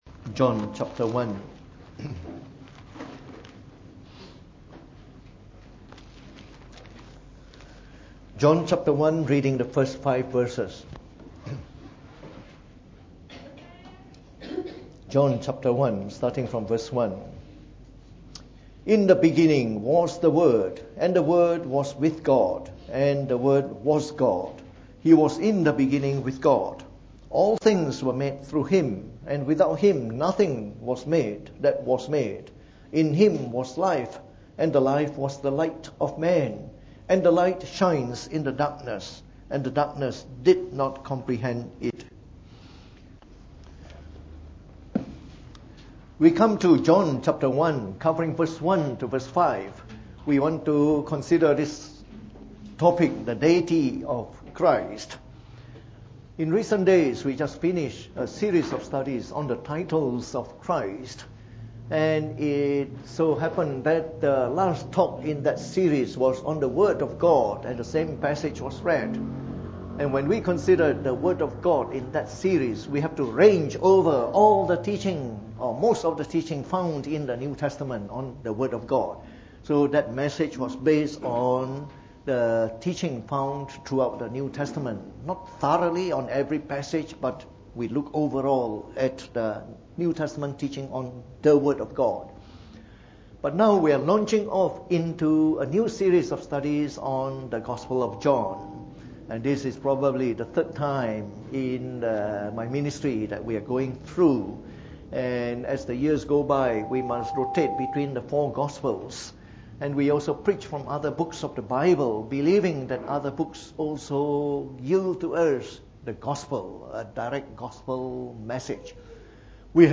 From our series on the Gospel of John delivered in the Evening Service.